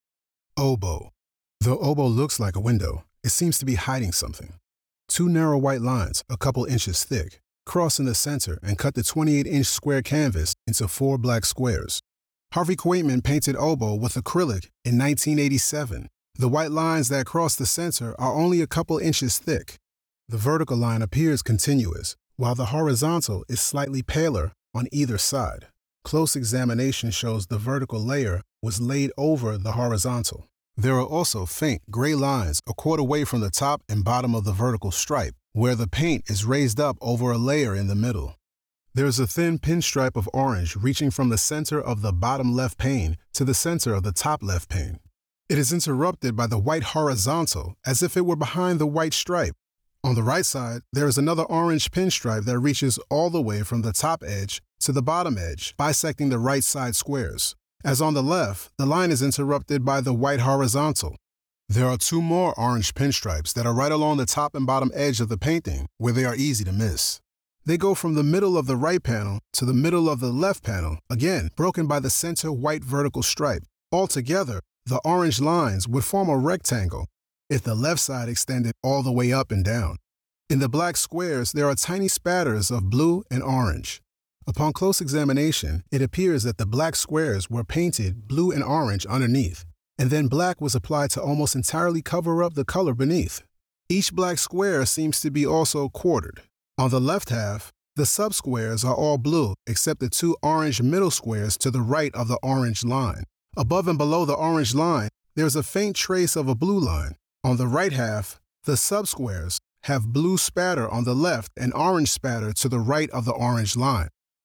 Audio Description (01:54)